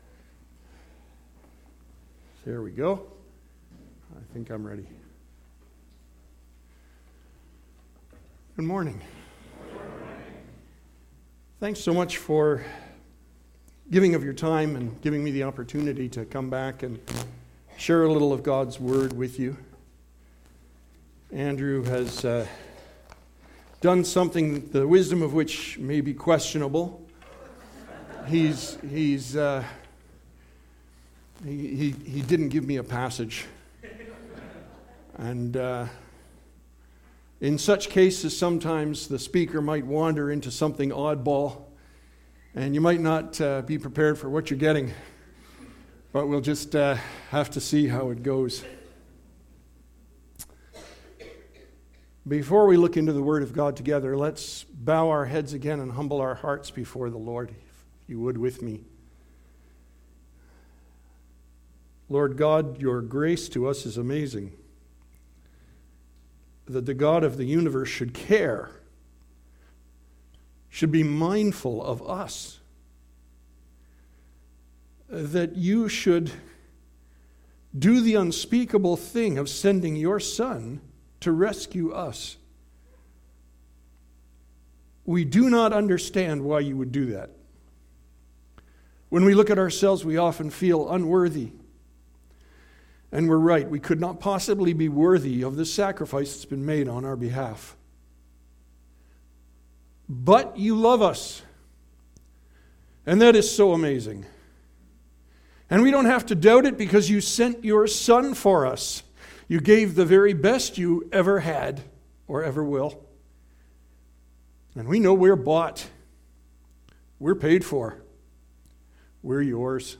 Sermon Audio and Video What in the World Is Going On?